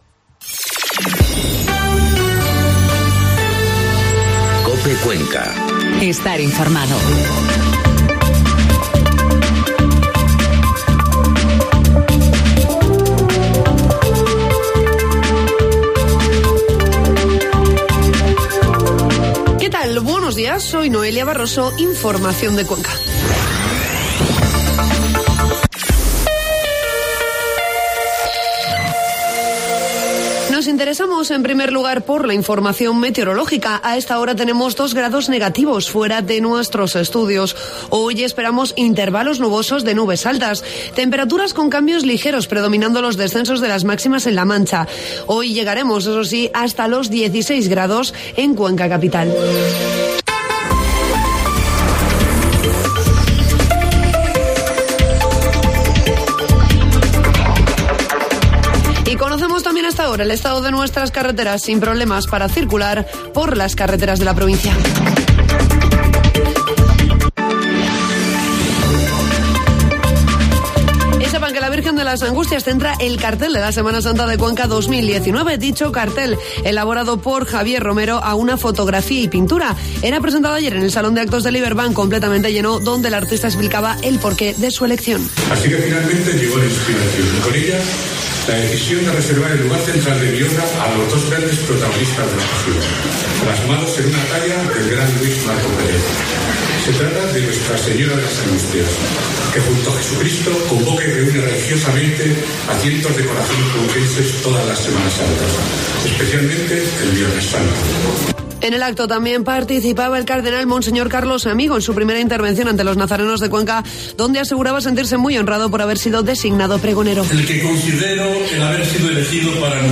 Informativo matinal COPE Cuenca 8 de febrero